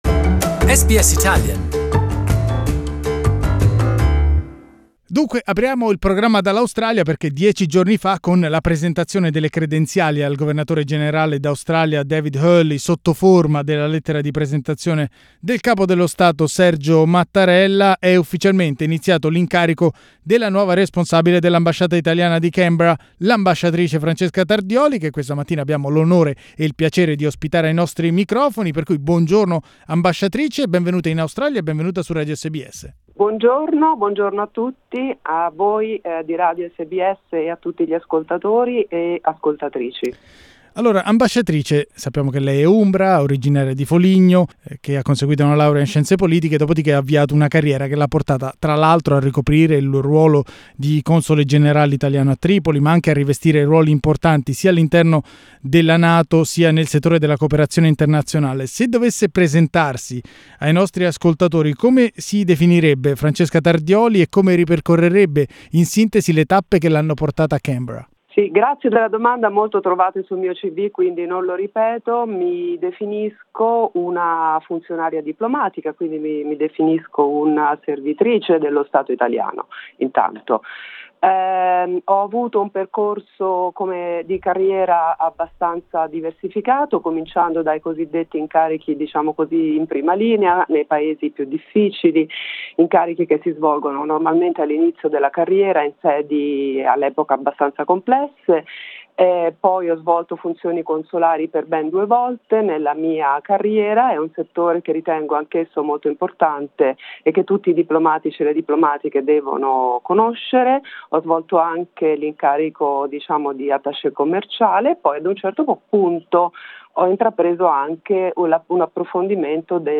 In this first interview since her appointment, the new Italian representative in Canberra talks about her projects and goals.